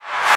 VEC3 Reverse FX
VEC3 FX Reverse 40.wav